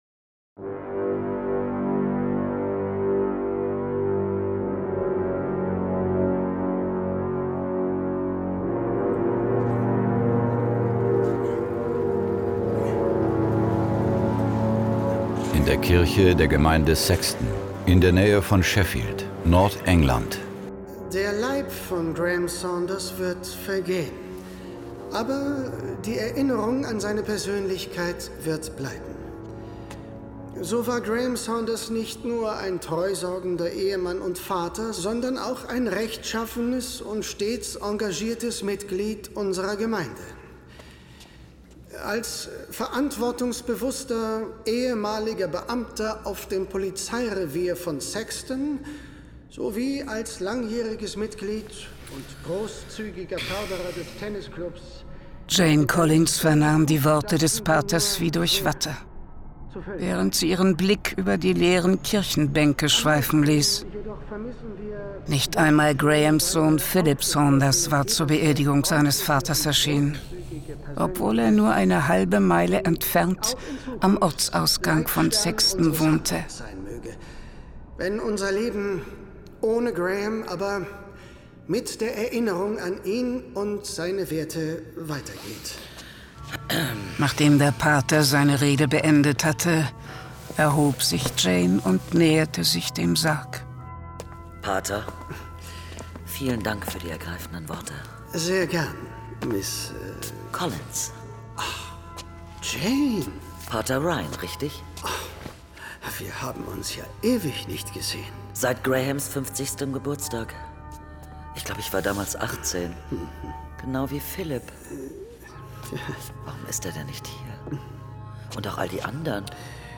John Sinclair Classics - Folge 43 Der Hexer mit der Flammenpeitsche . Hörspiel.